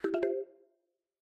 WirelessChargingStarted.ogg